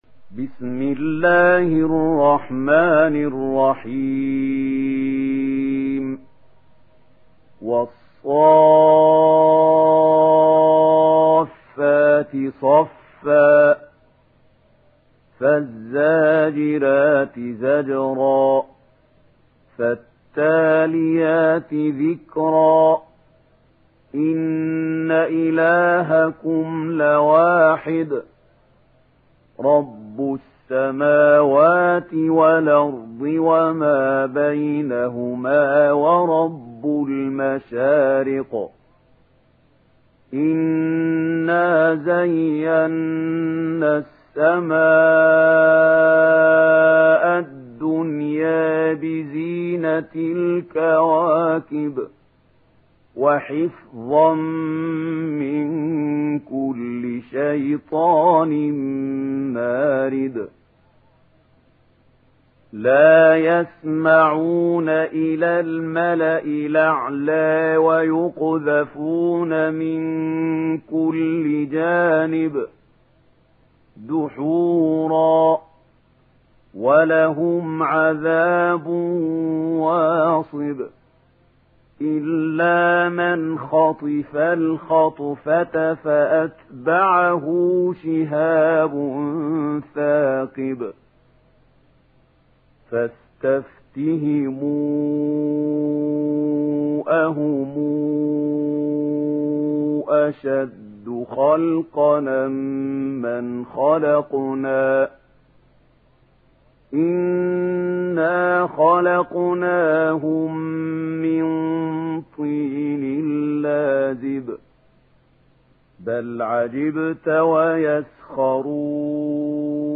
دانلود سوره الصافات mp3 محمود خليل الحصري روایت ورش از نافع, قرآن را دانلود کنید و گوش کن mp3 ، لینک مستقیم کامل